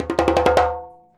100DJEMB09.wav